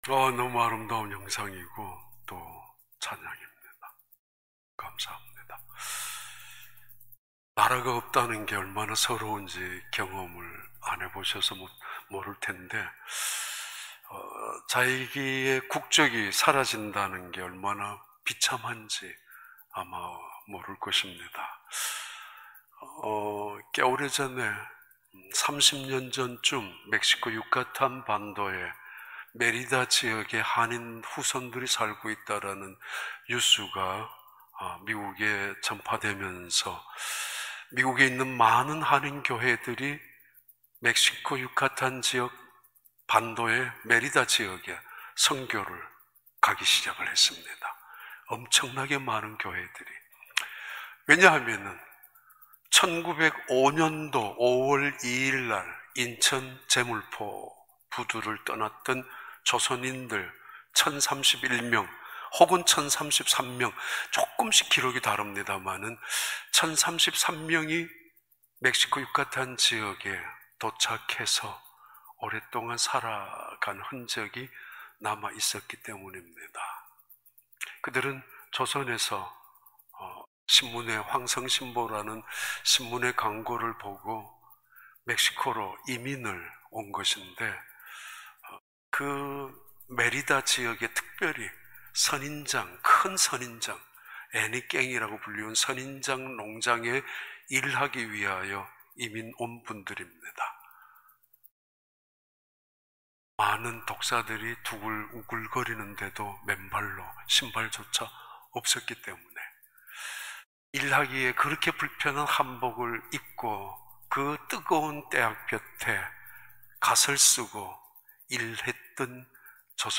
2021년 1월 10일 주일 4부 예배